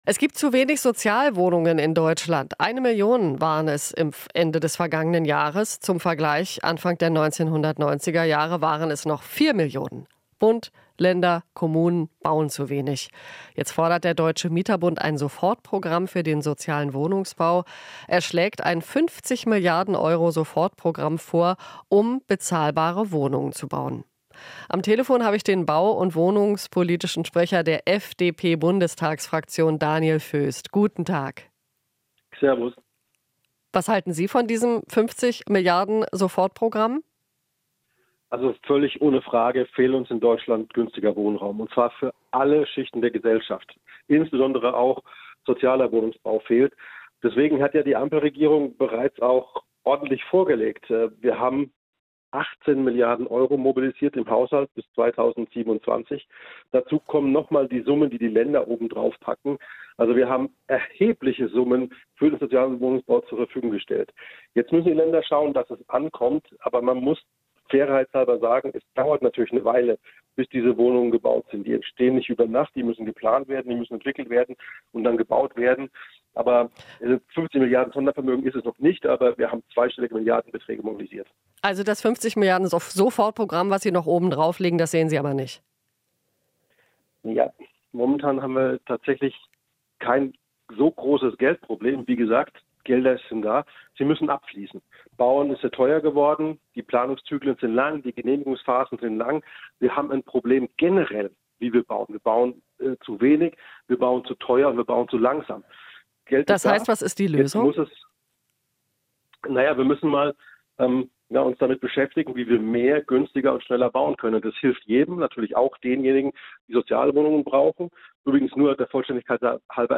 Interview - Föst (FDP): Es wird zu wenig, zu teuer und zu langsam gebaut